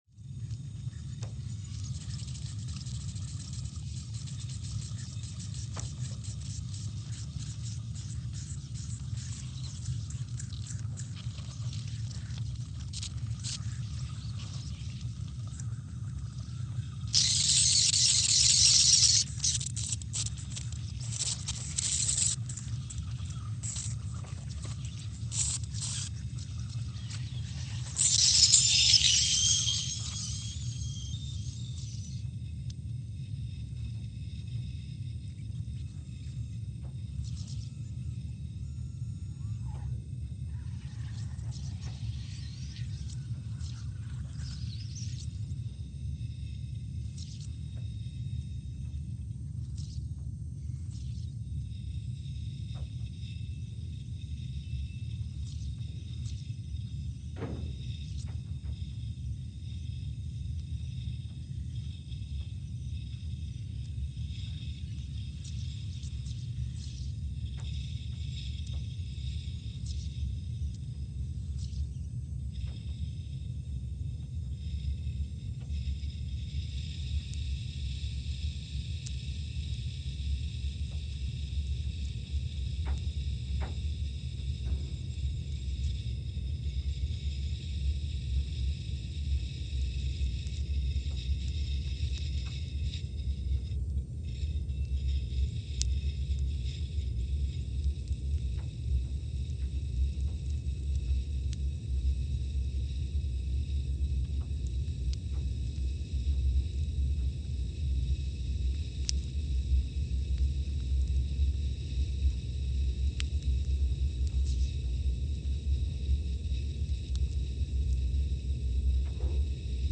Scott Base, Antarctica (seismic) archived on November 10, 2020
Sensor : CMG3-T
Speedup : ×500 (transposed up about 9 octaves)
Loop duration (audio) : 05:45 (stereo)
SoX post-processing : highpass -2 90 highpass -2 90